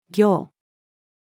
暁-female.mp3